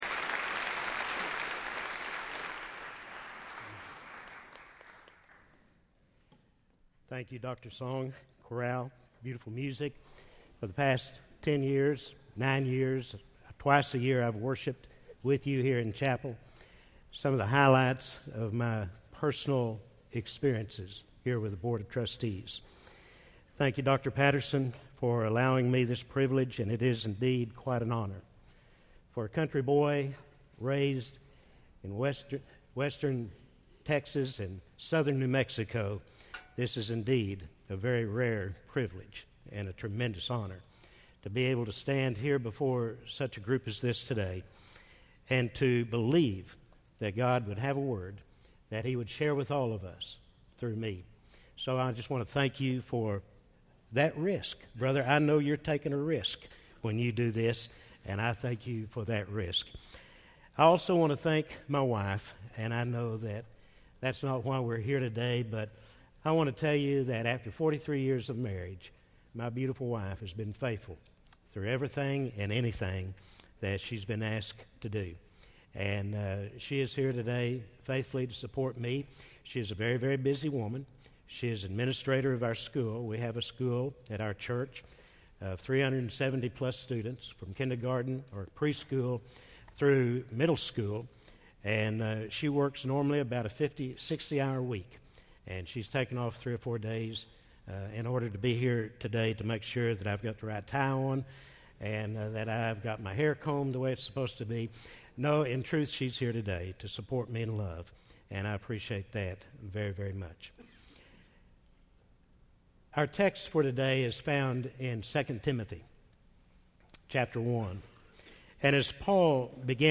speaking on II Timothy 1 in SWBTS Chapel on Wednesday April 7, 2010